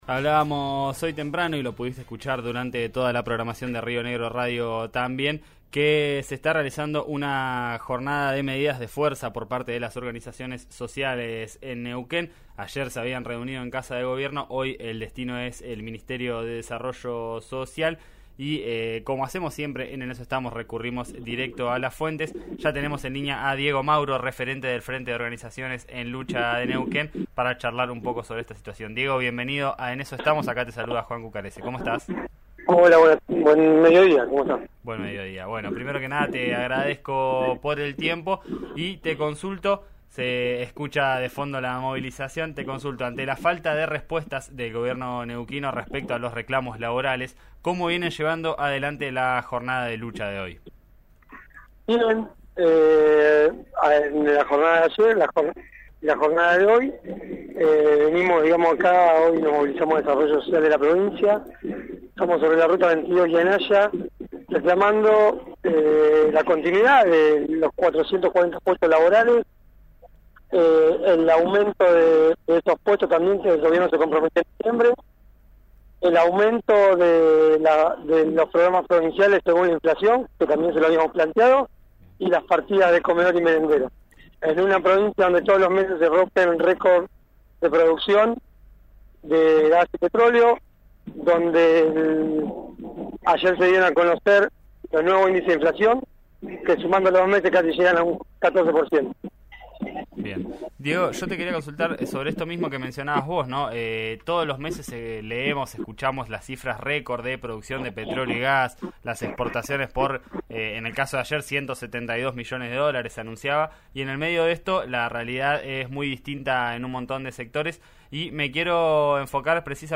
«Fue un funcionario que lleva y trae», pero no puede tomar decisiones, cuestionó en diálogo con RÍO NEGRO RADIO.